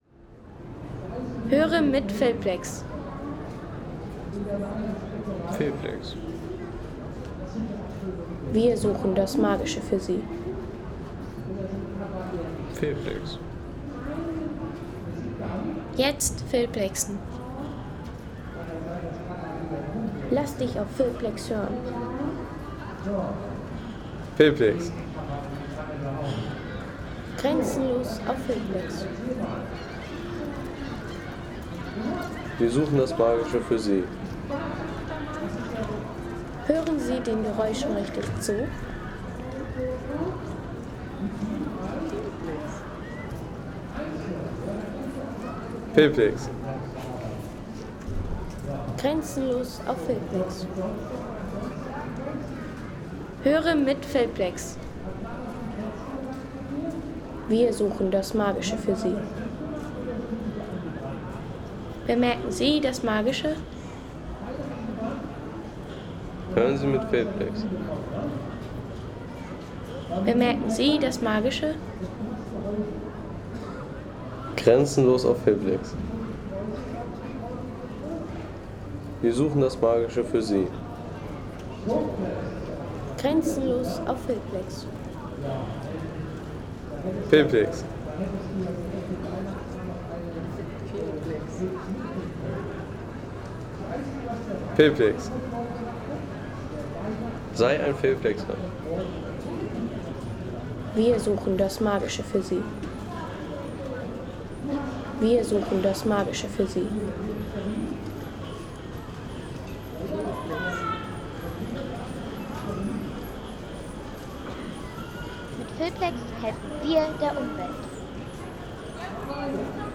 Weihnachtszauber in Schwerin – Stimmen des Märchenmarktes.